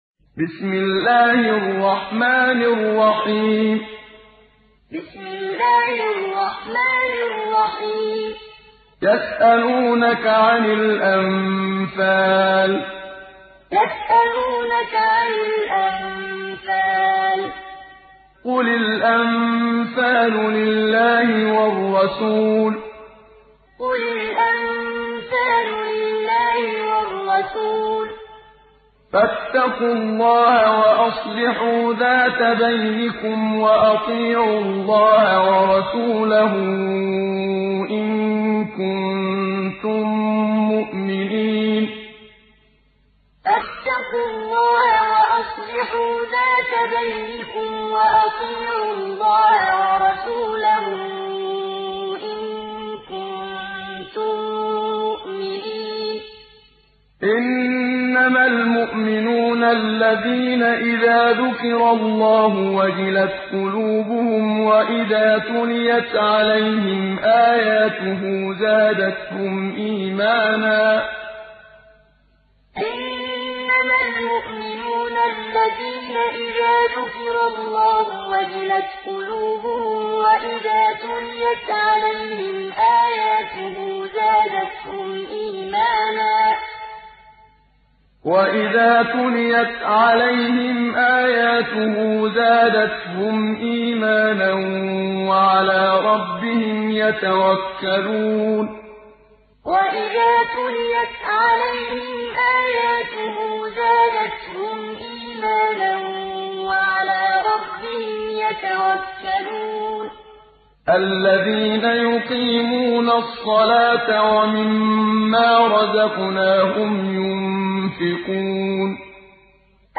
دانلود سوره الأنفال محمد صديق المنشاوي معلم